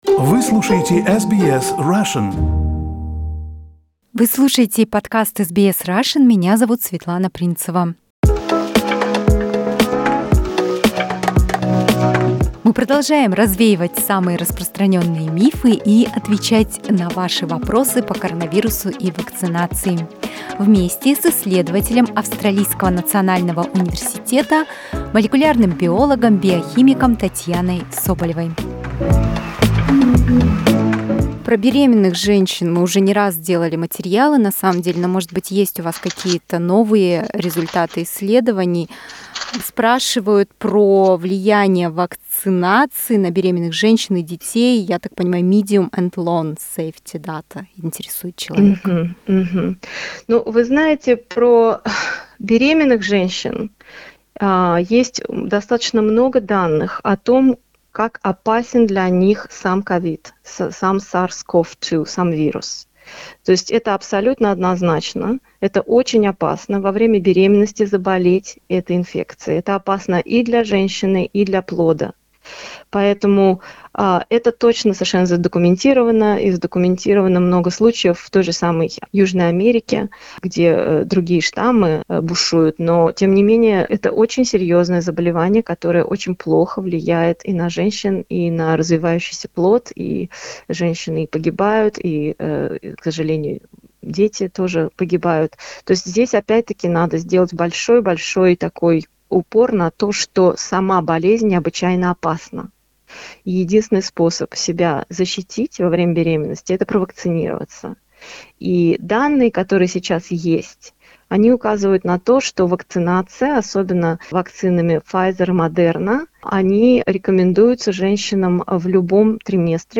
Australian scientists are answering the questions from our listeners about coronavirus and COVID-19 vaccination.